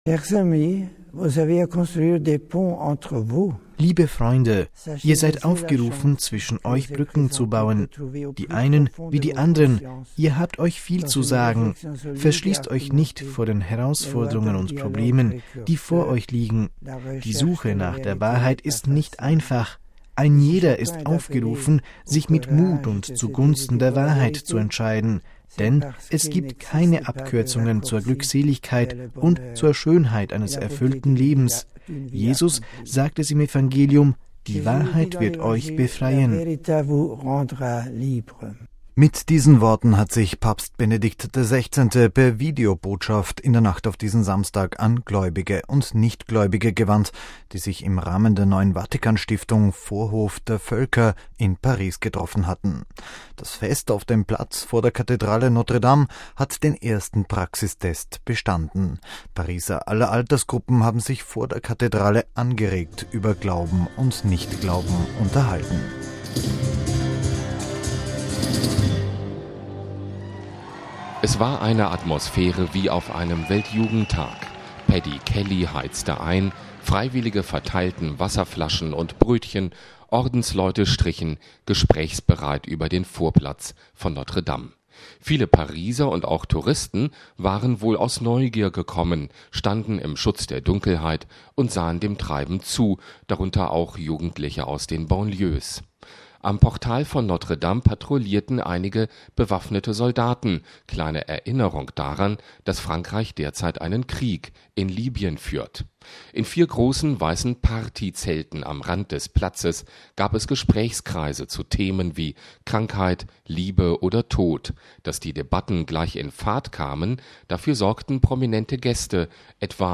MP3 Mit einem Fest vor Notre Dame von Paris hat die neue Vatikanstiftung „Vorhof der Völker“ in der Nacht auf Samstag den ersten Praxistest „in freier Wildbahn“ bestanden. In Zelten vor der Kathedrale diskutierten Pariser aller Altersgruppen angeregt über Glauben und Nichtglauben heute.